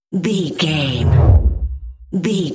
Whoosh deep
Sound Effects
dark
futuristic
intense